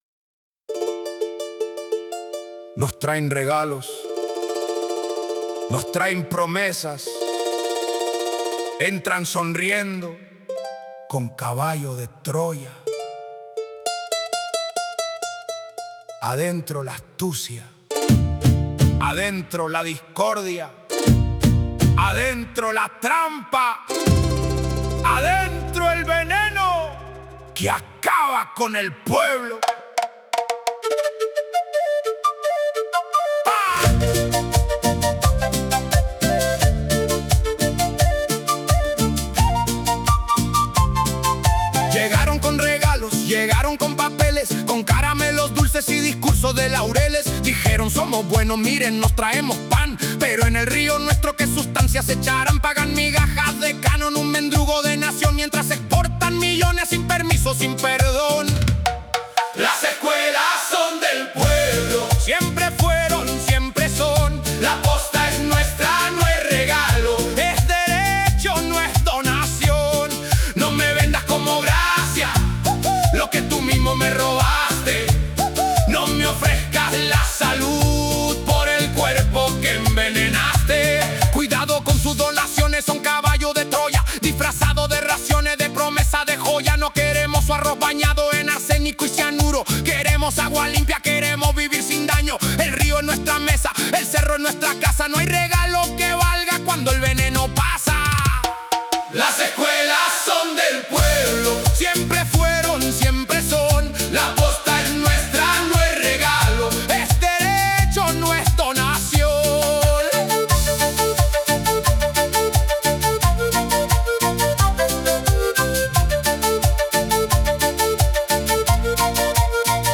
Huayno